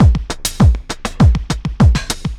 pulse tombeat 100bpm 05.wav